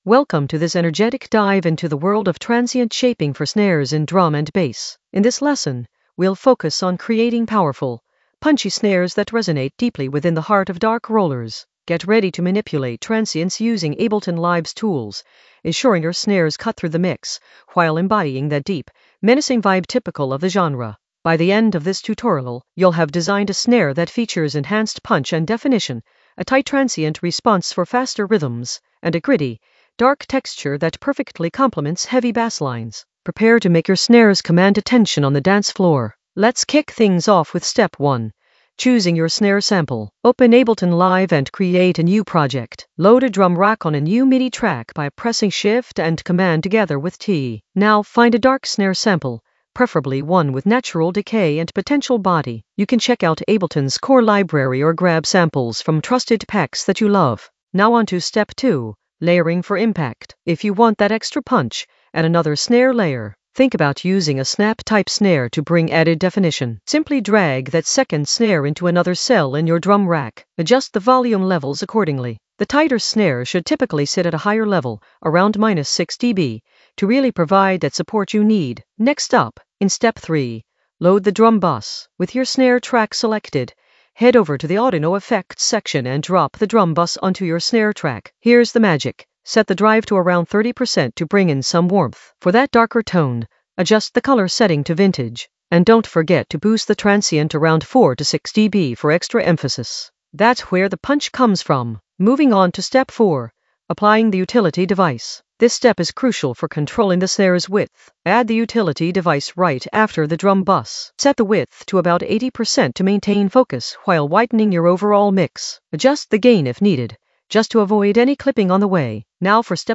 An AI-generated intermediate Ableton lesson focused on Transient shaping for snares for dark rollers in the Drums area of drum and bass production.
Narrated lesson audio
The voice track includes the tutorial plus extra teacher commentary.